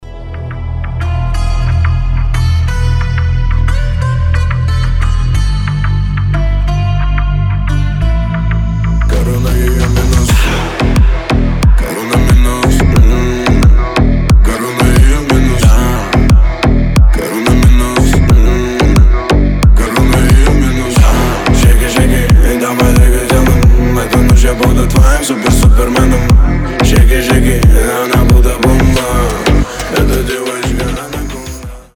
нарастающие
басы
восточные
качающие
G-House